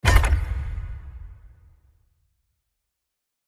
battle_skill_btn.mp3